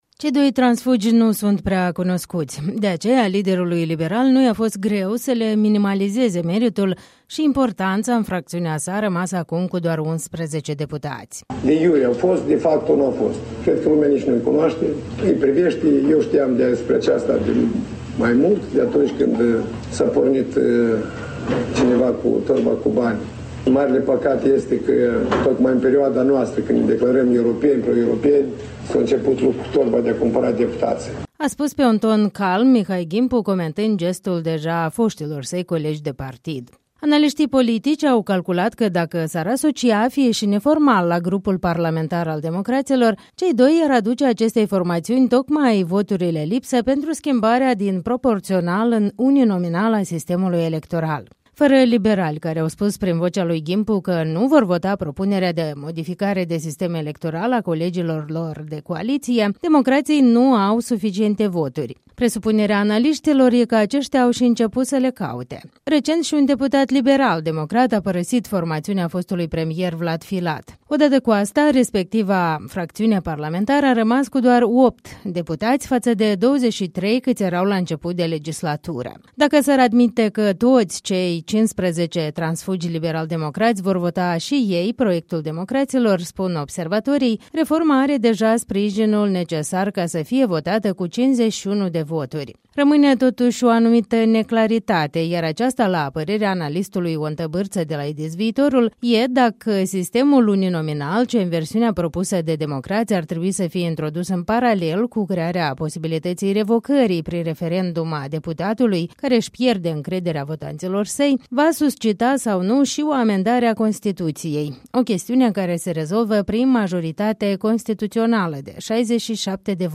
„De iure, au fost, de facto, nu au fost. Lumea nu-i cunoaşte. Îi priveşte. Eu ştiu de aceasta de mai demult, încă de când cineva s-a pornit cu torba cu bani. Marele păcat e că tocmai în perioada noastră, când ne declarăm pro-europeni, s-a început umblatul cu torba de a cumpăra deputaţii”, a spus pe un ton calm Mihai Ghimpu, comentând gestul deja foştilor săi colegi de partid.